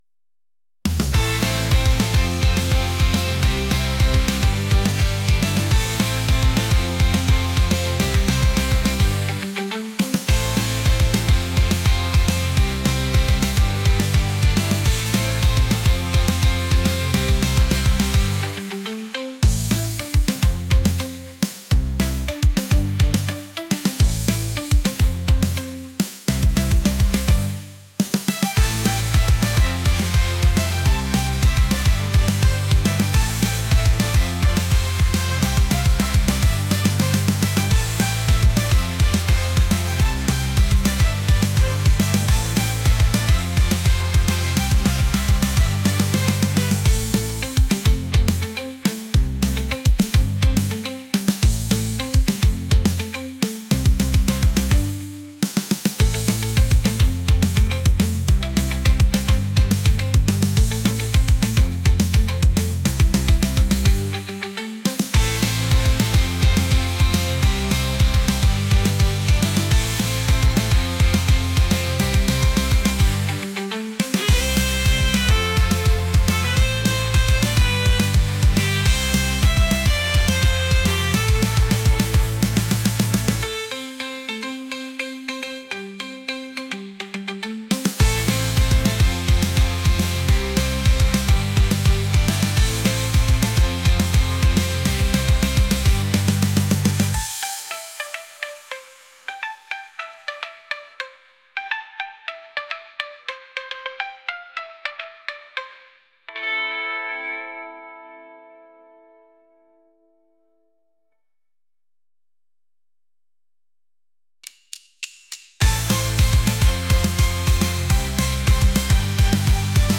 catchy | pop | energetic